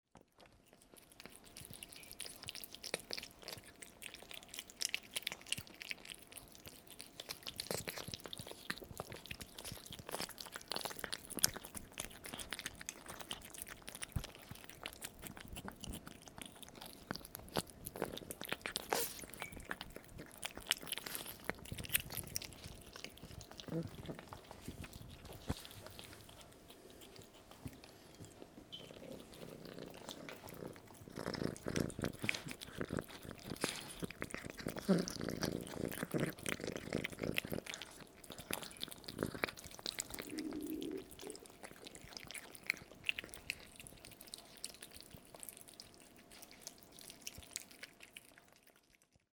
Cats eating – Ears Are Wings
two babycats hastily gulping their breakfast